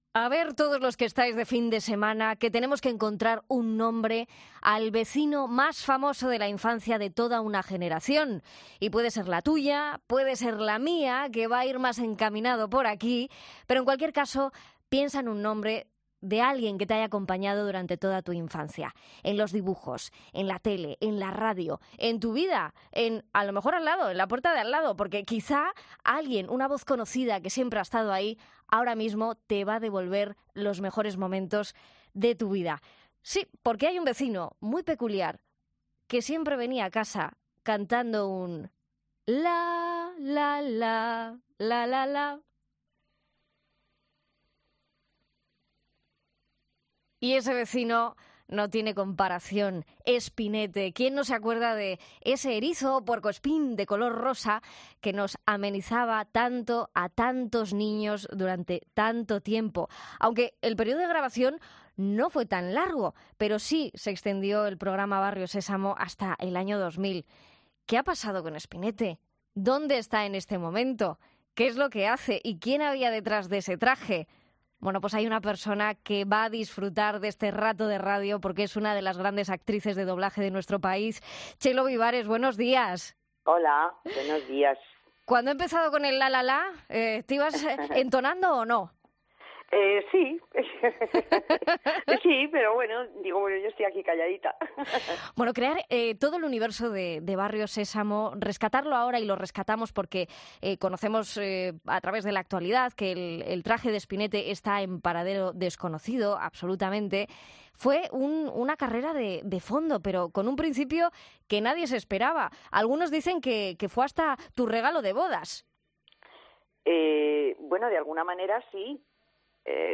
Entrevista a la actriz que dio vida a Espinete, Chelo Vivares